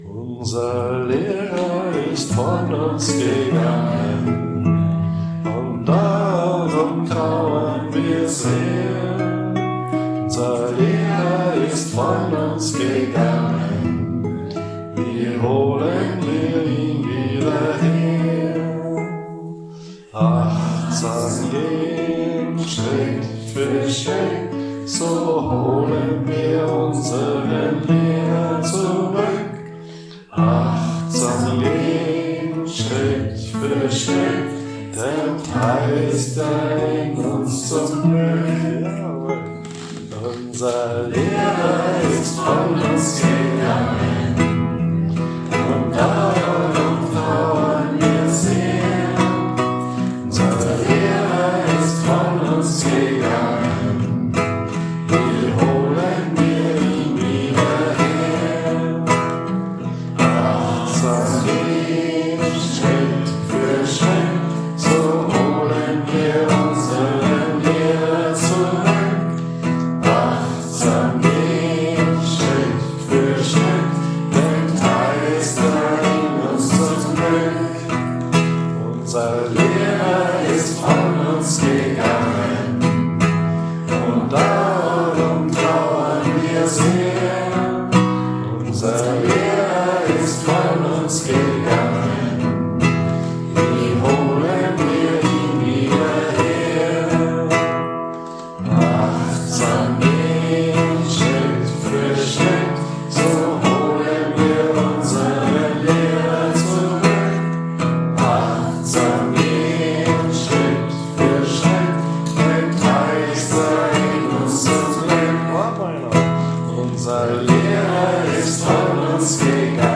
Mantrisches Singen
Tag der Gehmeditation am 20. April 2024